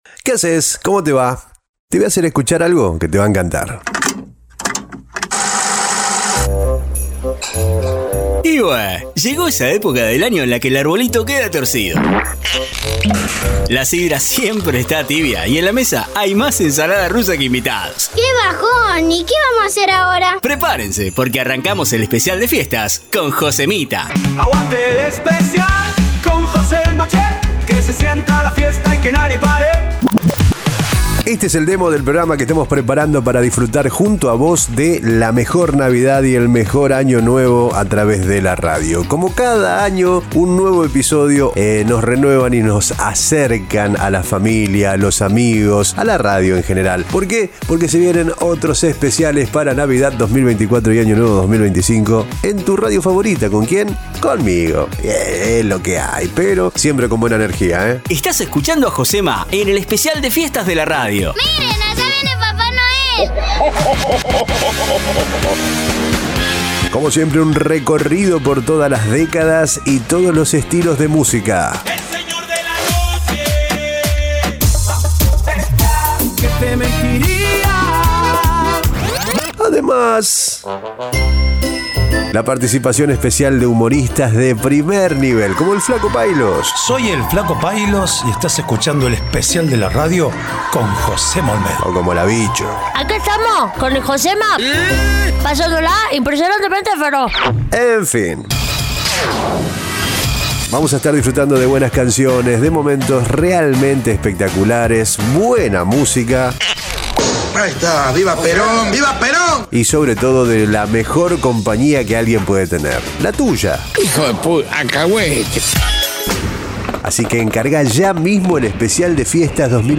Con toda la onda de un programa en vivo, por eso, nos vas a sentir bien cerca.
6hs seguidas de la mejor compañía con sensación de vivo….!!!
SENSACIÓN DE VIVO